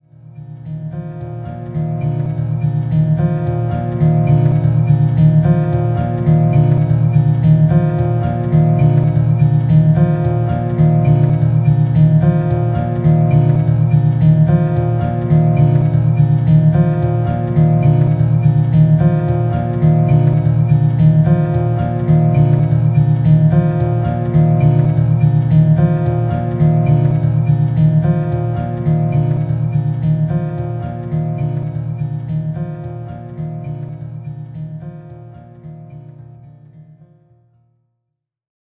The first one is the loop in the original pitch